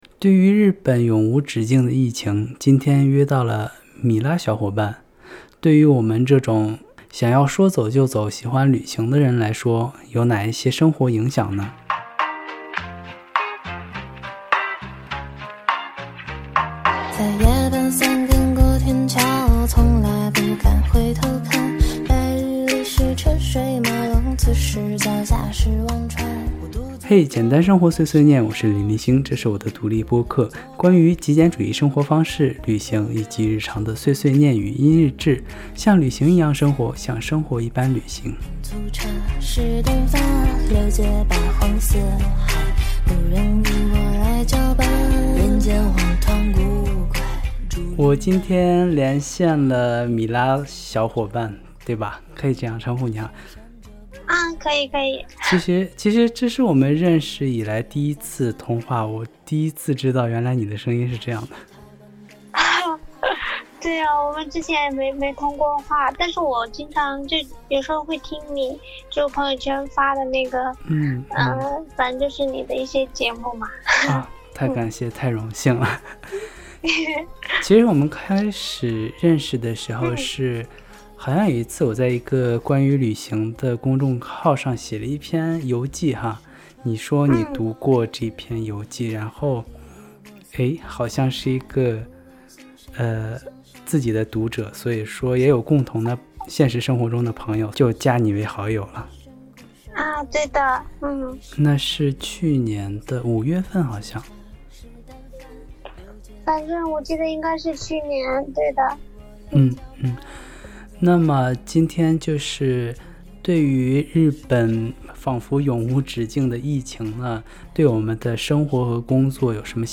对谈日本疫情系列3：东京热爱旅行的小仙女